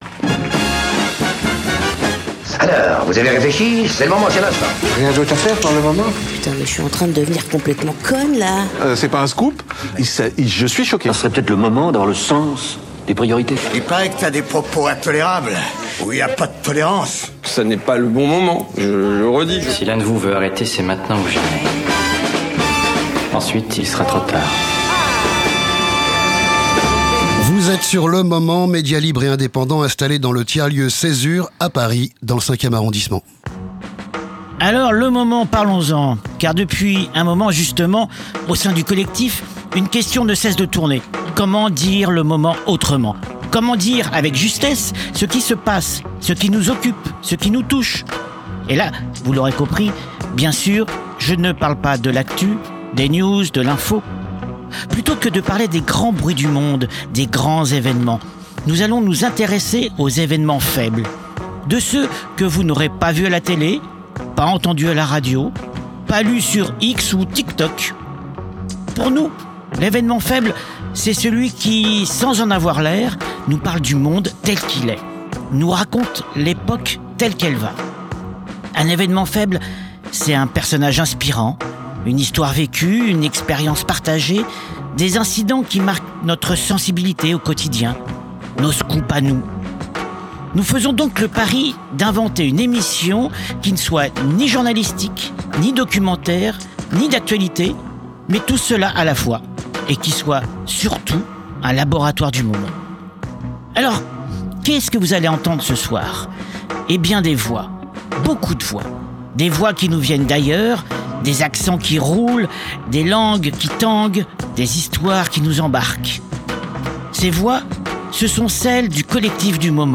De Paris à l’autre bout du monde, chaque épisode capte des voix singulières, des témoignages inattendus, des récits de vie, des réflexions sociales et des expériences humaines.
Avec ses reportages immersifs, ses chroniques décalées et ses invités surprise, C’est Le Moment vous plonge dans des sujets de société souvent négligés : le validisme, la place des personnes handicapées dans le travail, les luttes contemporaines, et bien plus encore.